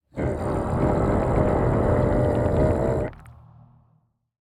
Minecraft Version Minecraft Version snapshot Latest Release | Latest Snapshot snapshot / assets / minecraft / sounds / mob / warden / angry_4.ogg Compare With Compare With Latest Release | Latest Snapshot
angry_4.ogg